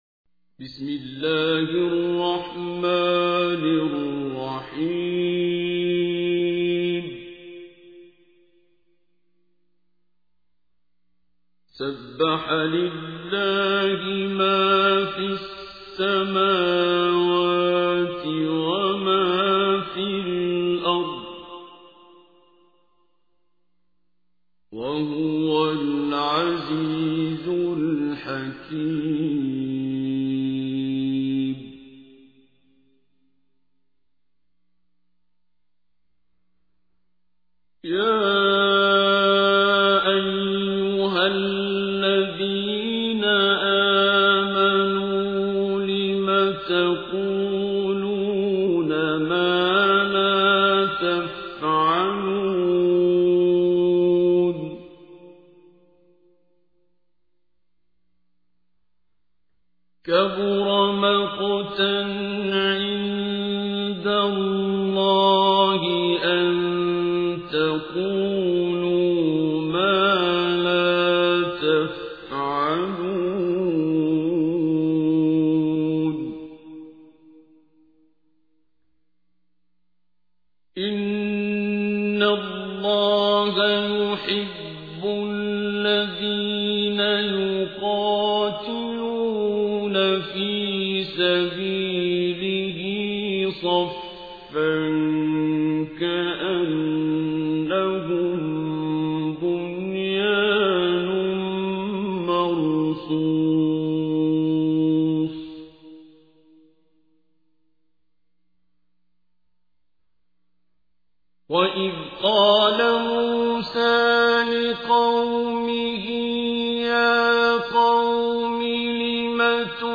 تحميل : 61. سورة الصف / القارئ عبد الباسط عبد الصمد / القرآن الكريم / موقع يا حسين